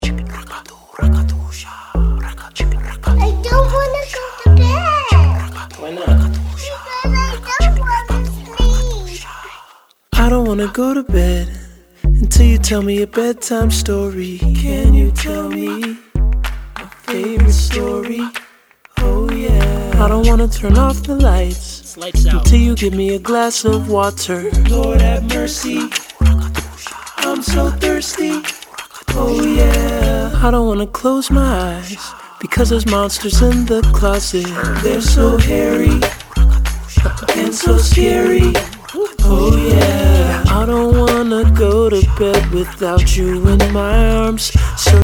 Hip Hop, soul and electronic